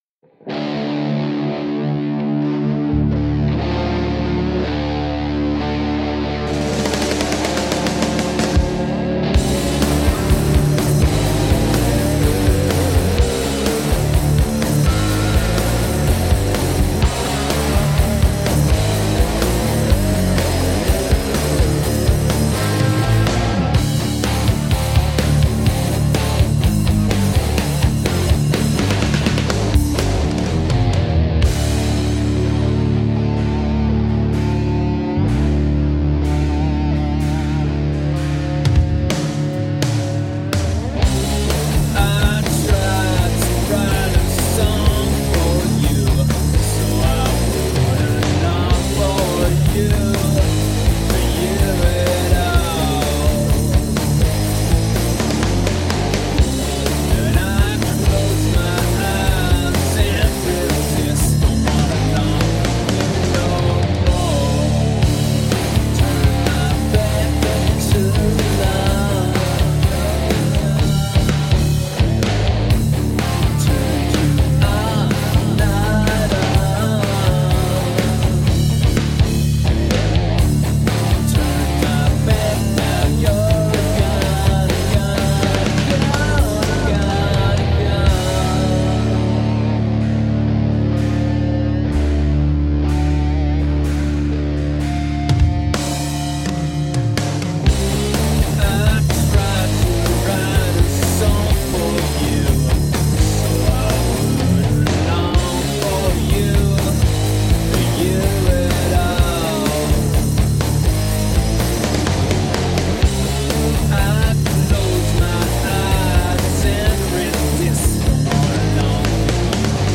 Brooding, melodic grunge.
Tagged as: Hard Rock, Metal, Indie Rock